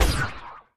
etfx_shoot_magic.wav